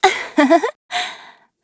Xerah-Vox_Happy1.wav